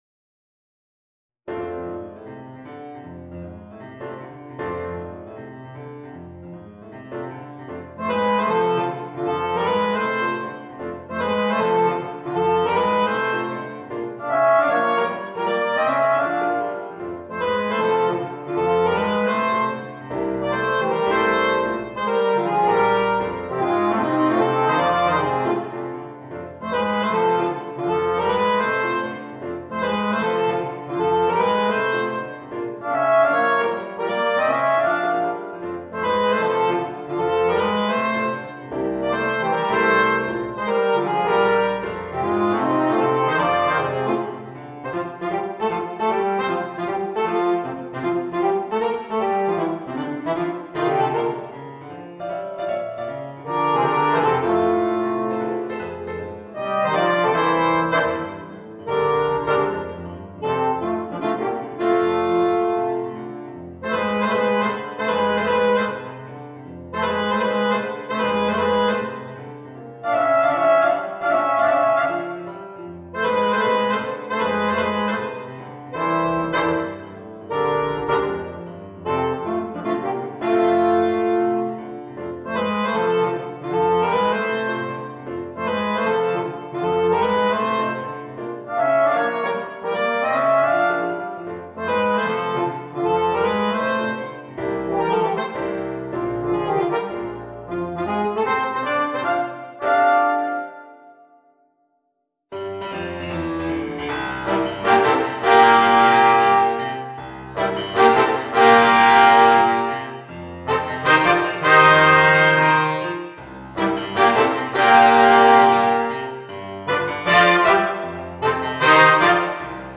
Voicing: Alto Saxophone, Trombone w/ Audio